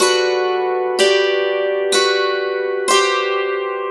Dulcimer06_123_G.wav